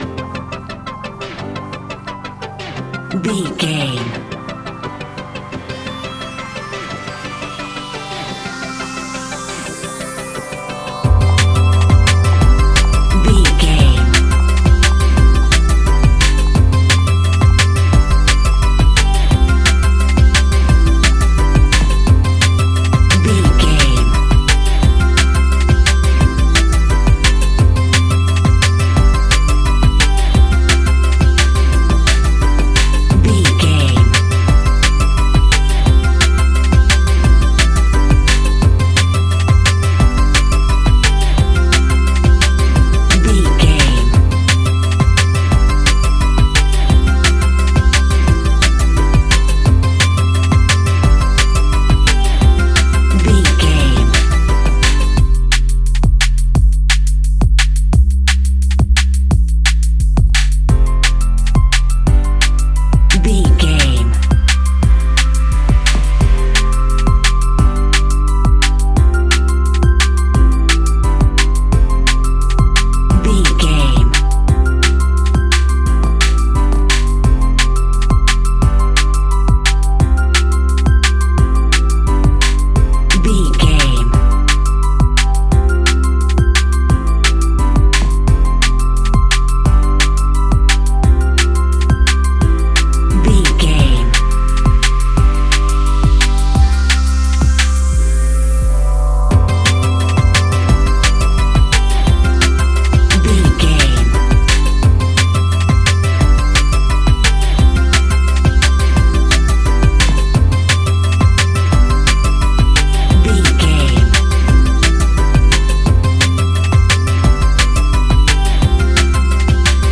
Ionian/Major
electronic
techno
trance
synths
synthwave